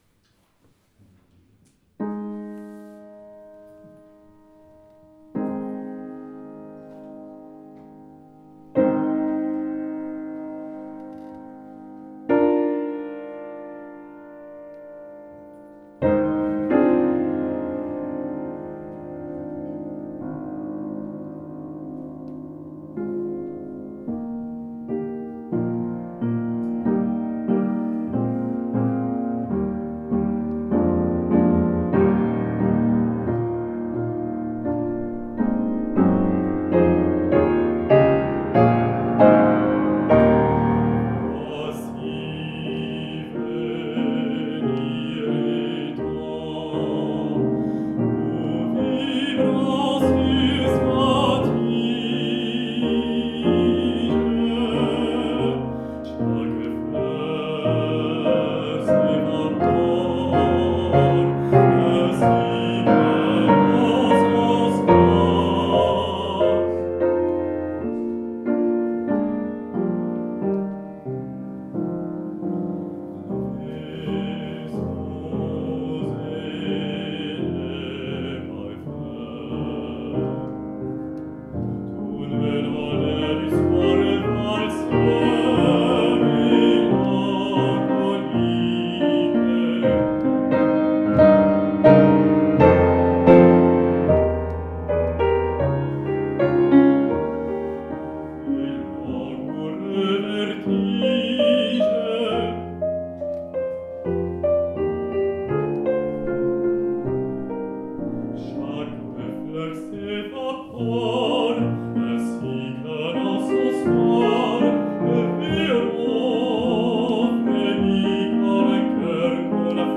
Bariton